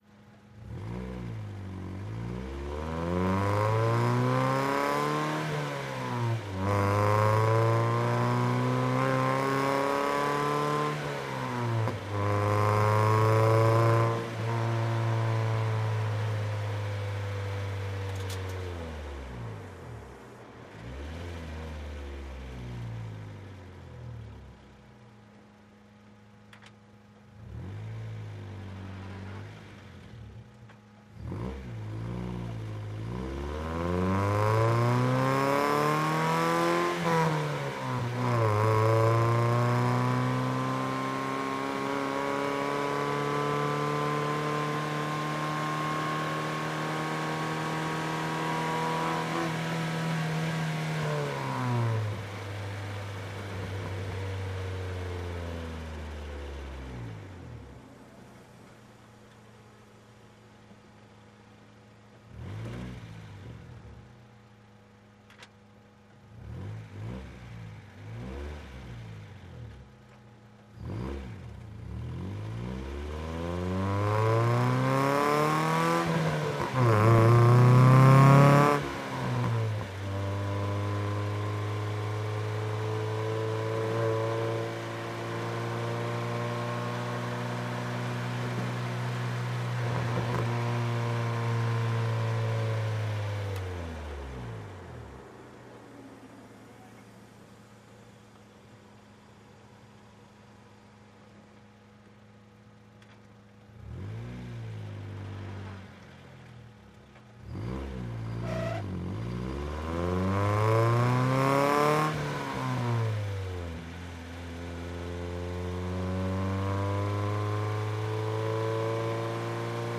Integra onboard - engine, accelerate bursts with shifts & coasting